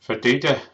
It is most commonly used in Donegal in the northwest of Ireland, where it’s pronounced something like /
fa’duːdə/.